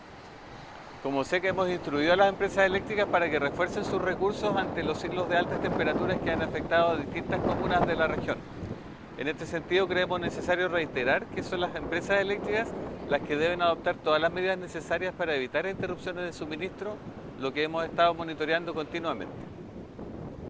El director regional de la SEC, Manuel Cartagena, destacó la fiscalización activa que se realizará para verificar el cumplimiento de estas medidas.
CUNA-DIRECTOR-REGIONAL-SEC.mp3